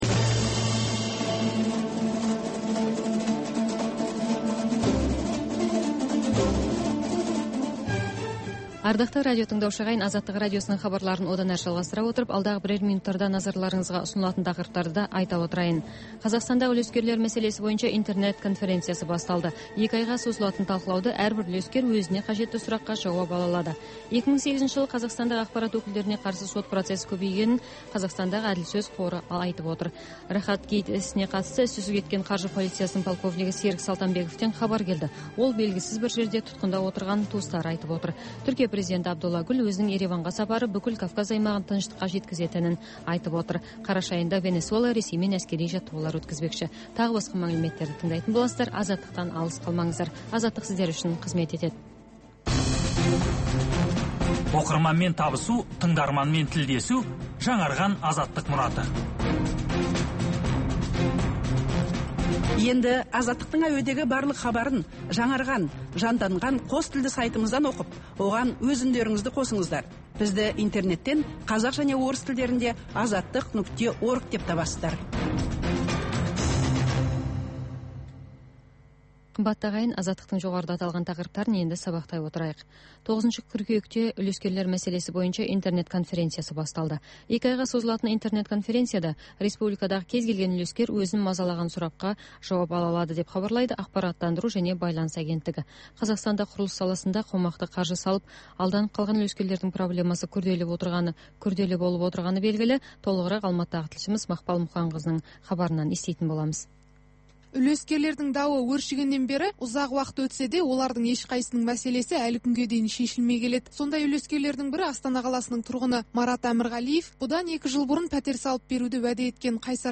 Бүгінгі күннің өзекті мәселесі, пікірталас, оқиға ортасынан алынған репортаж, қазақстандық және халықаралық сарапшылар пікірі, баспасөзге шолу.